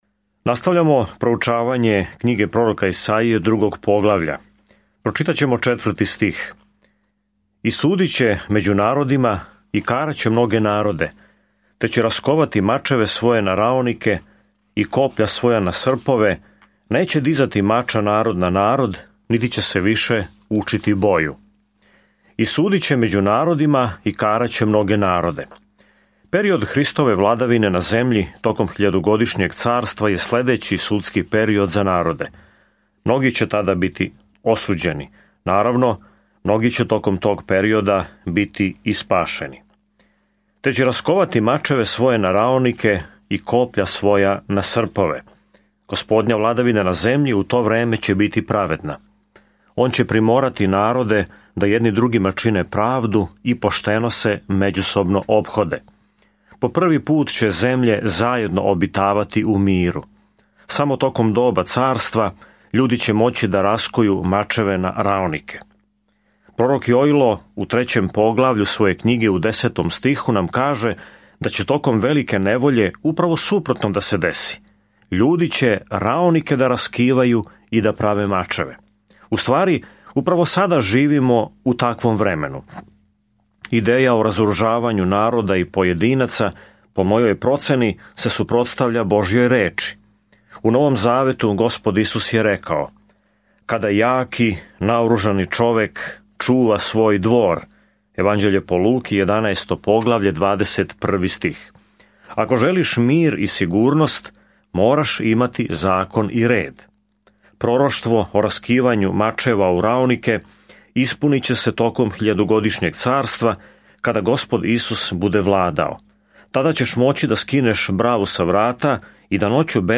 Sveto Pismo Knjiga proroka Isaije 2:4-22 Knjiga proroka Isaije 3:1-8 Dan 3 Započni ovaj plan Dan 5 O ovom planu Назван „пето јеванђеље“, Исаија описује долазећег краља и слугу који ће „носити грехе многих“ у мрачно време када ће политички непријатељи завладати Јудом. Свакодневно путујте кроз Исаију док слушате аудио студију и читате одабране стихове из Божје речи.